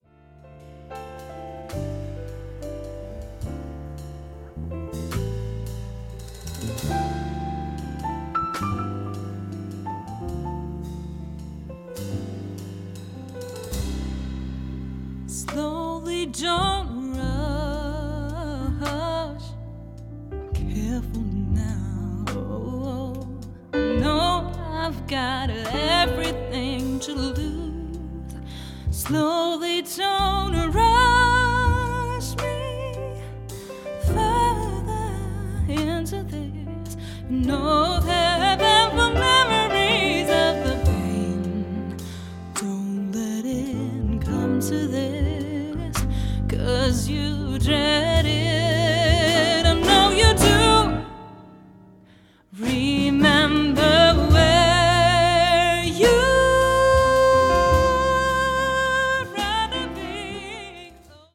voc
piano
bass
drums